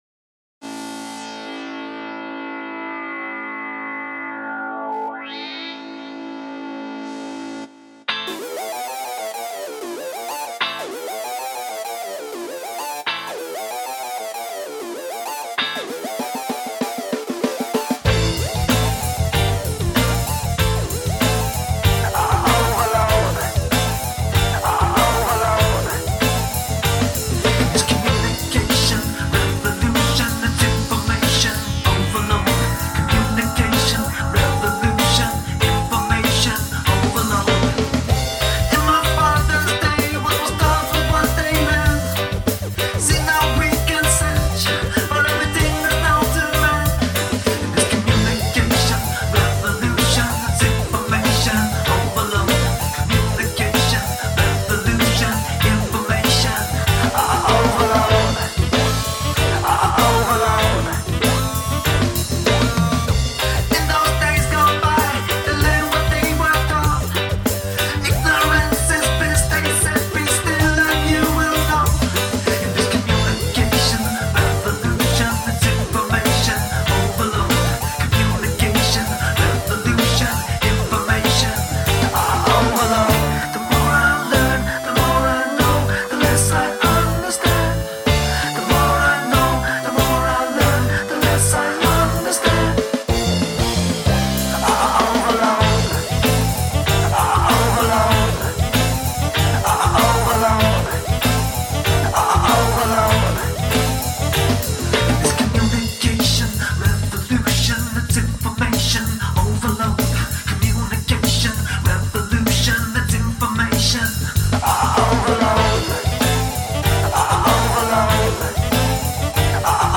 work in progress:
cho - Am Gm A# Ver - Cm G Bridge - Dm C G Am x 2 A# F C